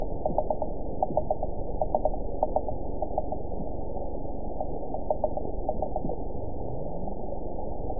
event 910653 date 01/23/22 time 22:17:01 GMT (3 years, 3 months ago) score 9.03 location TSS-AB05 detected by nrw target species NRW annotations +NRW Spectrogram: Frequency (kHz) vs. Time (s) audio not available .wav